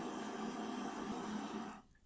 风机回路
描述：在带有Android平板电脑的浴室中录制，并使用Audacity进行编辑。
标签： 风扇 小便 管道 冲洗 冲洗 厕所 浴室 厕所 船尾
声道立体声